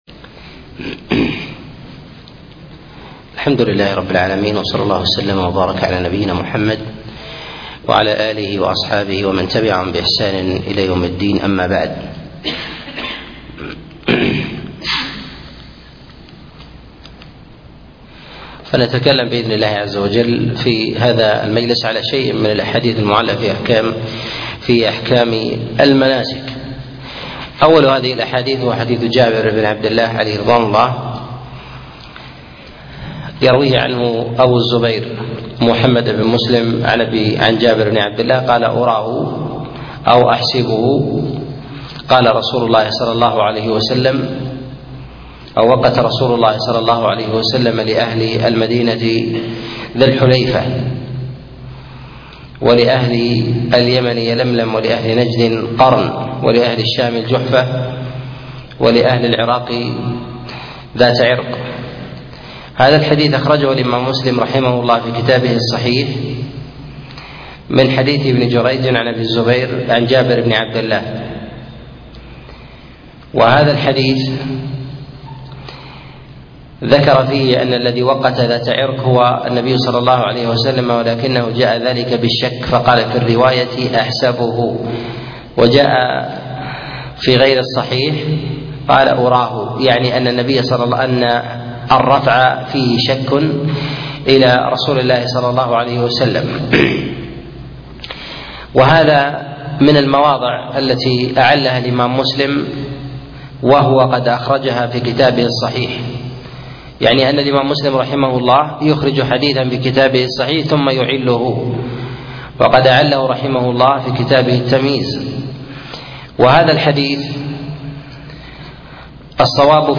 الأحاديث المعلة في الحج الدرس 3